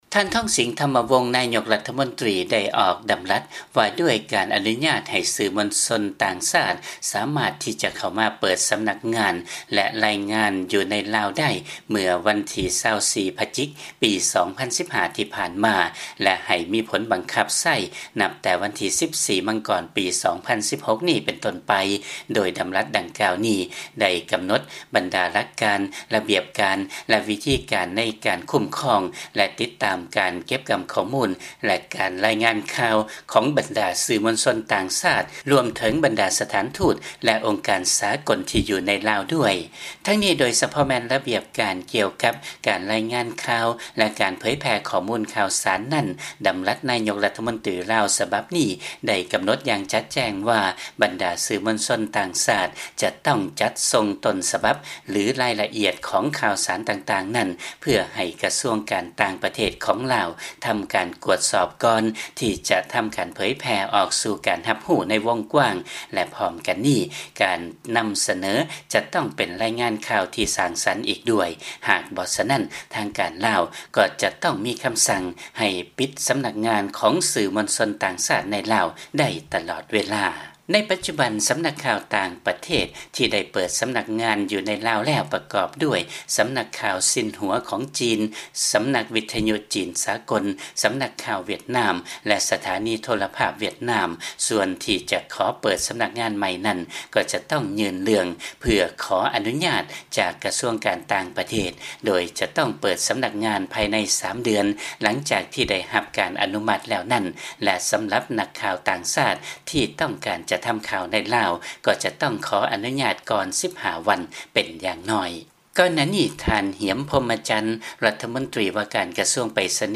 ເຊີນຟັງລາຍງານ ນາຍົກລາວ ອອກດຳລັດ ອະນຸຍາດໃຫ້ສື່ມວນຊົນຕ່າງຊາດ ເປີດສຳນັກງານ ແລະລາຍງານຂ່າວ ຢູ່ໃນລາວໄດ້.